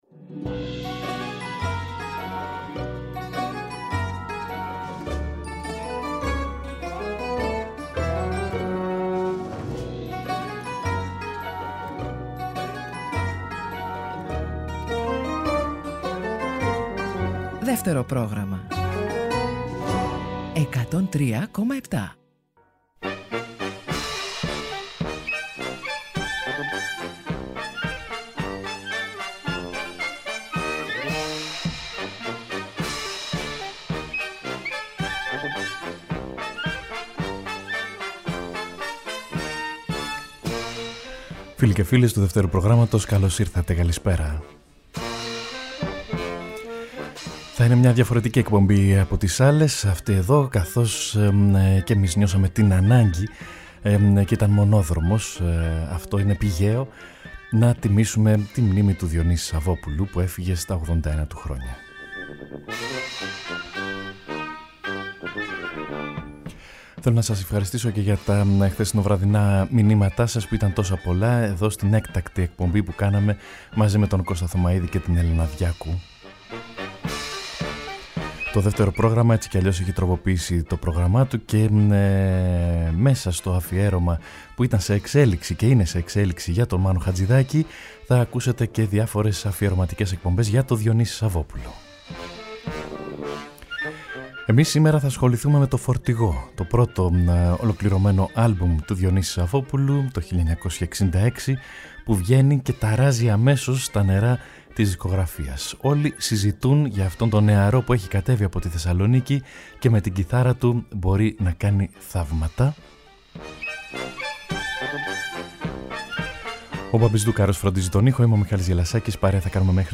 μια αφιερωματική εκπομπή παίζοντας όλα τα τραγούδια